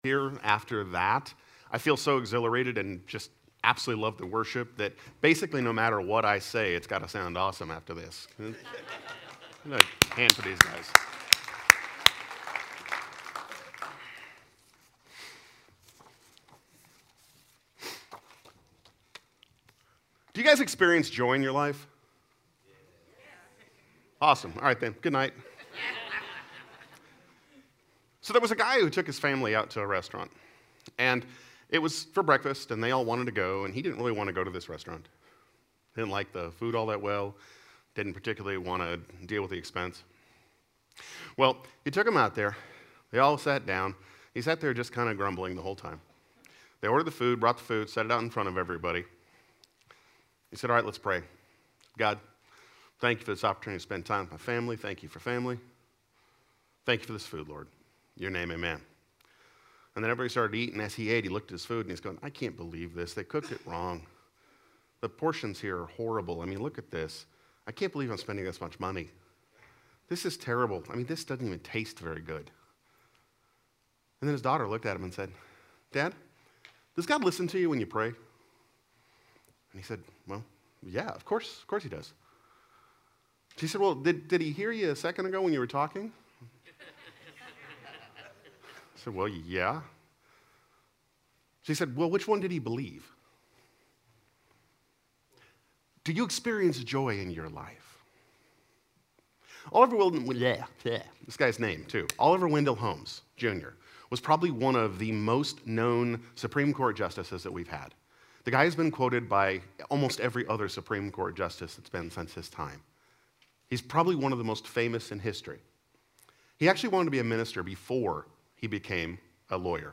Sermons
These are samples of sermons I have preached over the years.